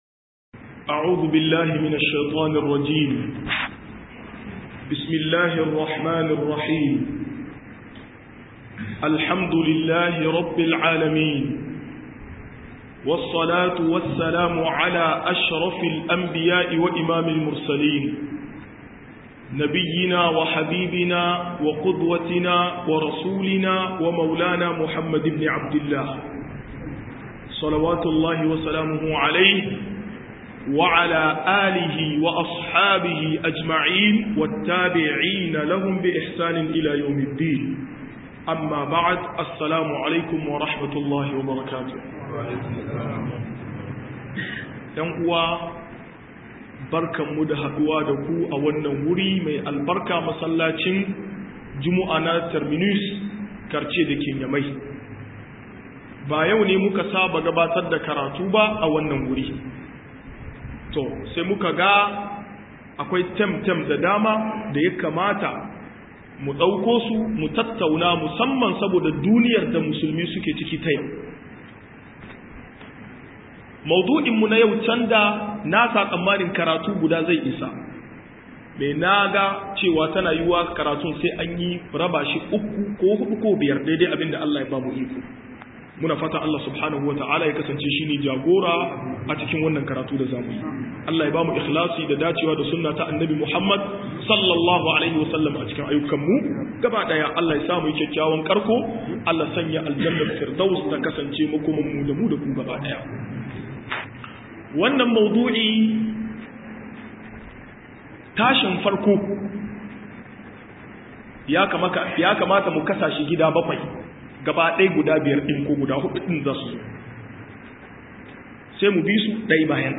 59--miracles de coran 1 - MUHADARA